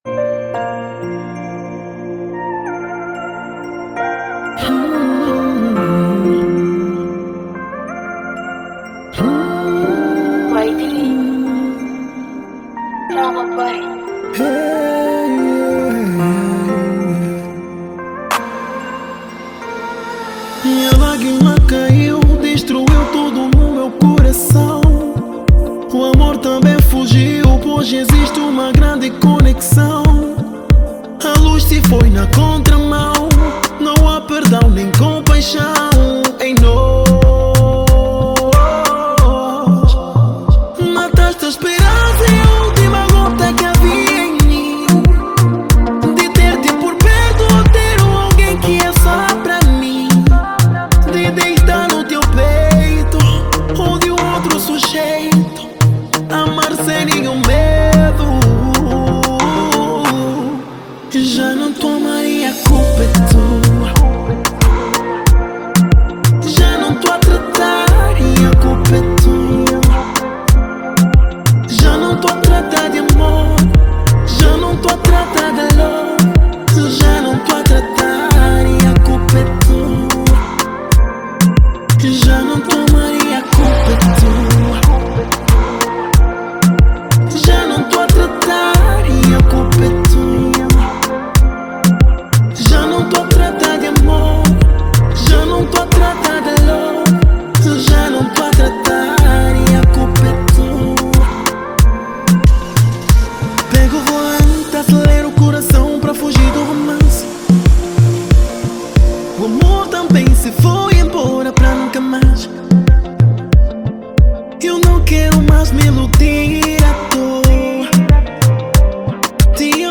urban soul